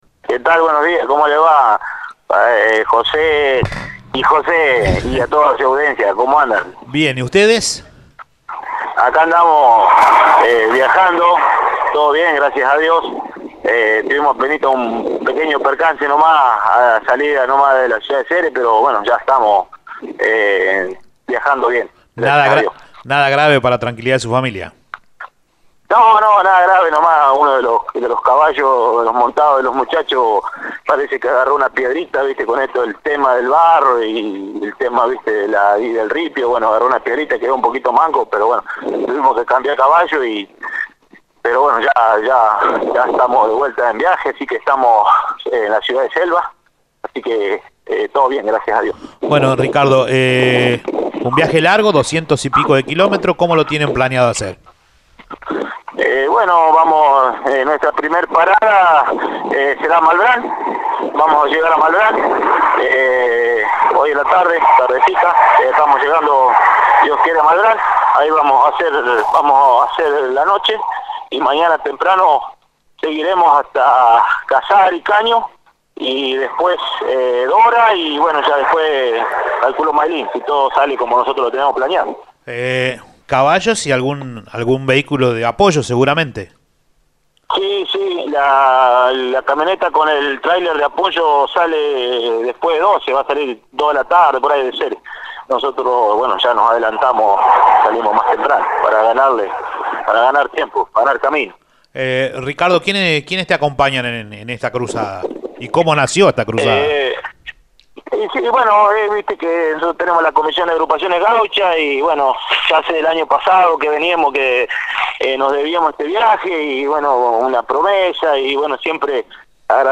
en Radio EME Ceres comentó sobre la experiencia religiosa.